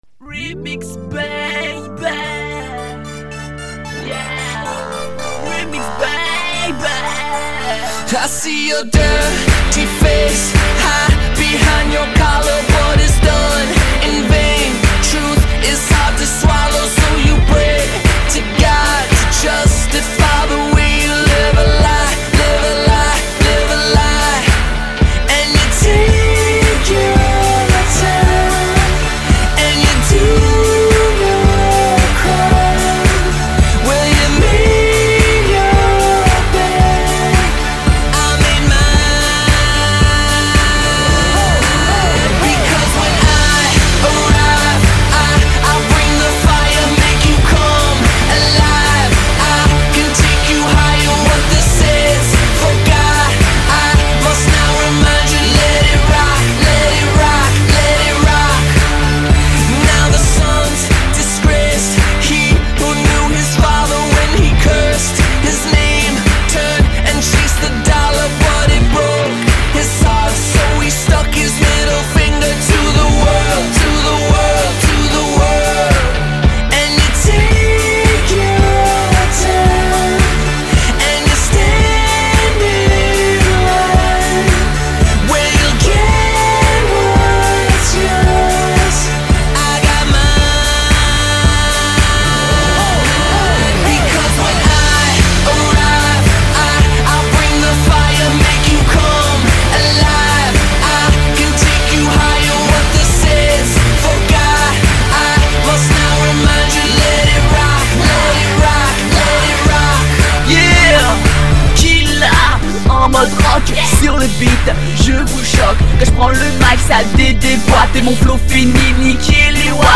influence Dirty South